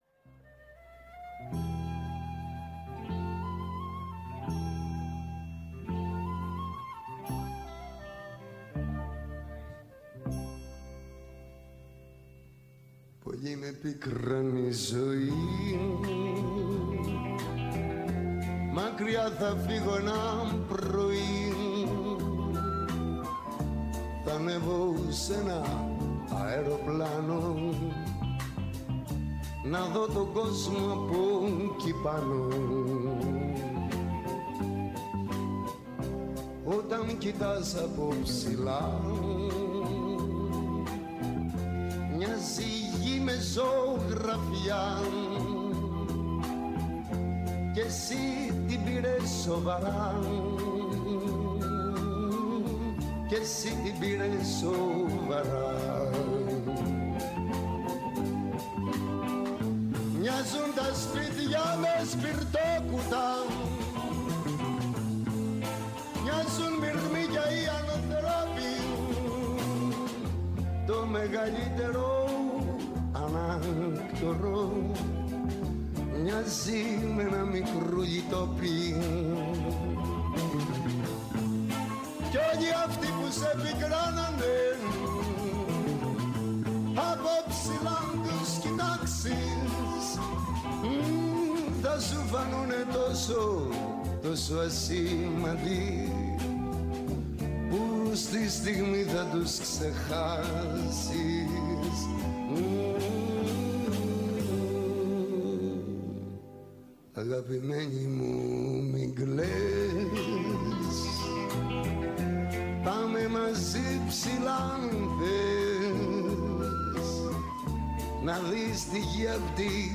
Έγκριτοι επιστήμονες, καθηγητές και αναλυτές μοιράζονται μαζί μας τις αναλύσεις τους και τις γνώσεις τους. ΠΡΩΤΟ ΠΡΟΓΡΑΜΜΑ Ναι μεν, Αλλα…